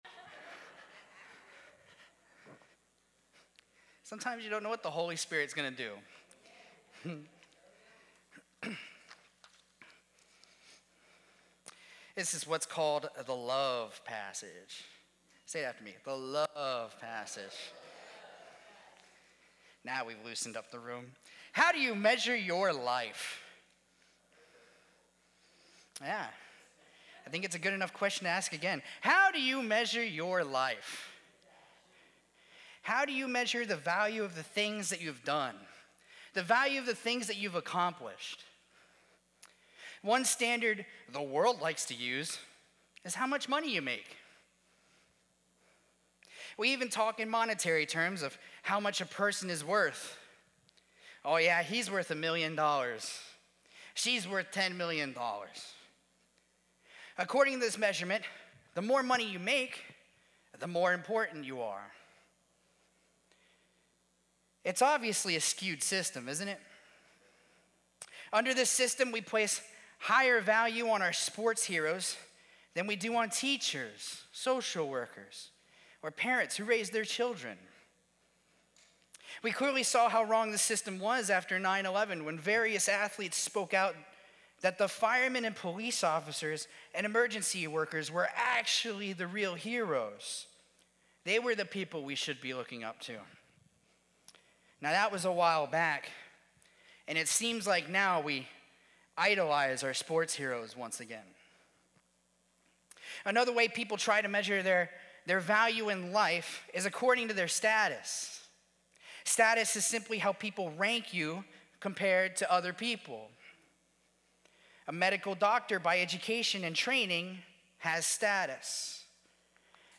Sermons | New Life Alliance Church